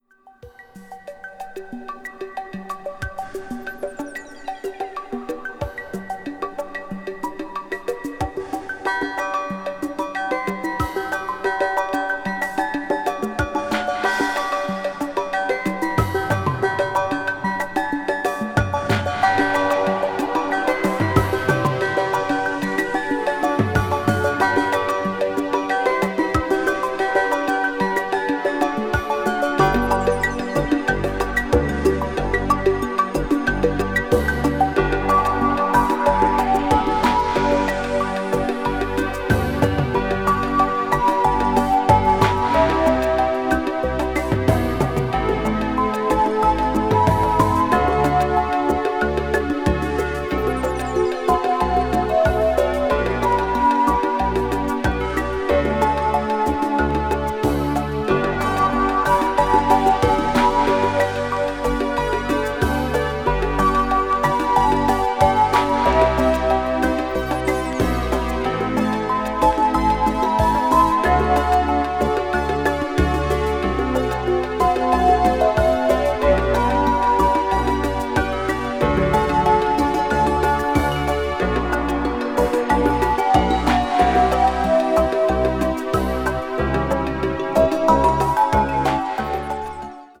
media : EX/EX(some slightly noise.)
ambient   german electronic   new age   synthesizer